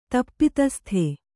♪ tappitasthe